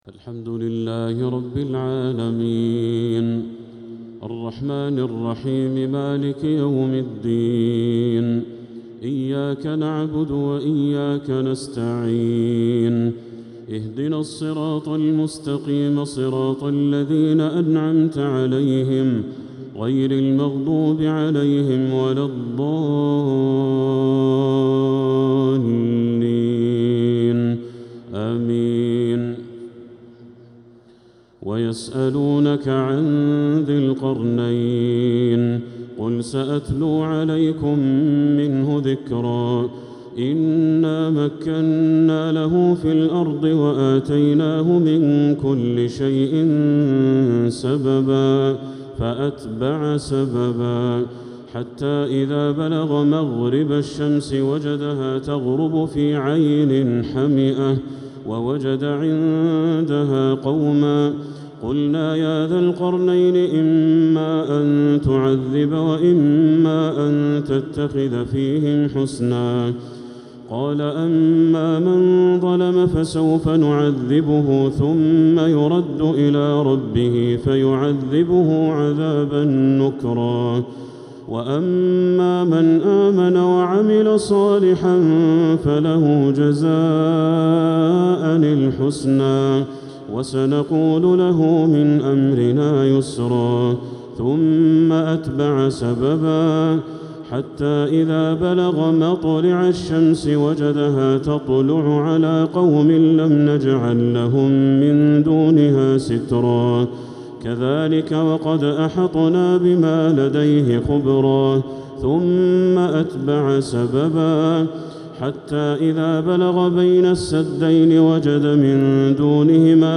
تراويح ليلة 21 رمضان 1447هـ من سورتي الكهف (83-110) و مريم (1-76) | Taraweeh 21st night Ramadan 1447H Surah Al-Kahf and Maryam > تراويح الحرم المكي عام 1447 🕋 > التراويح - تلاوات الحرمين